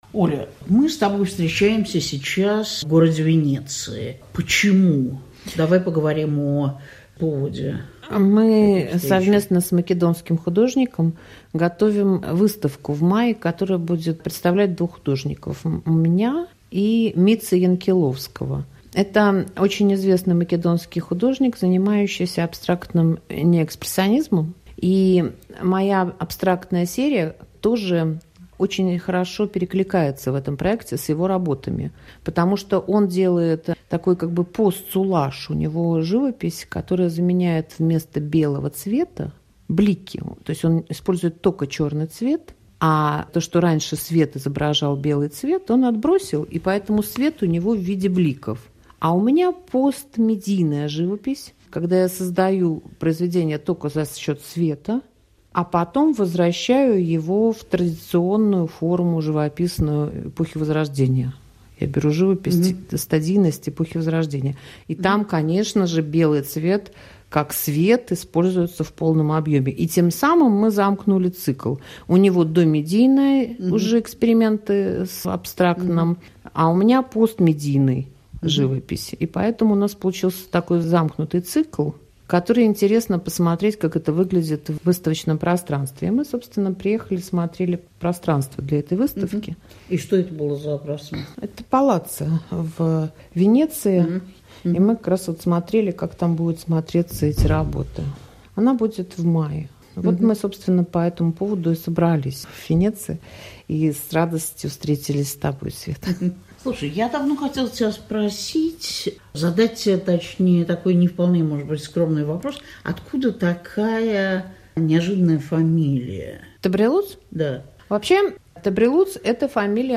Ольга Тобрелутс отвечает на вопросы